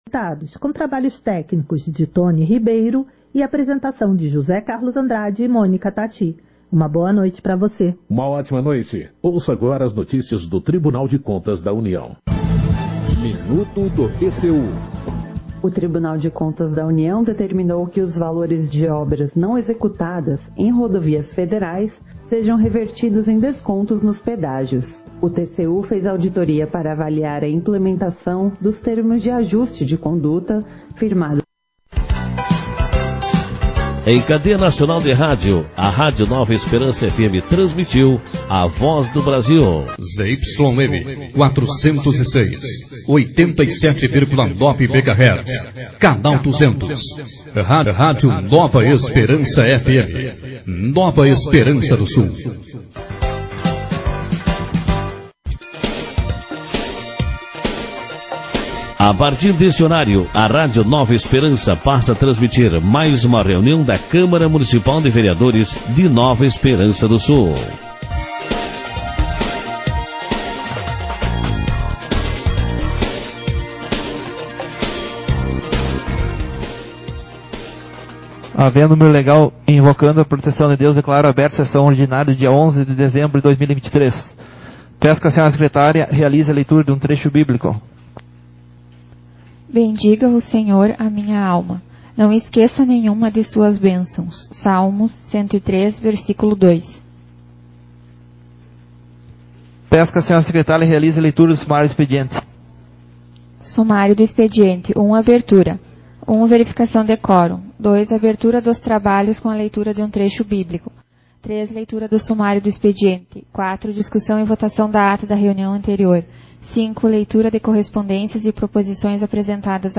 Sessão Ordinária 41/2023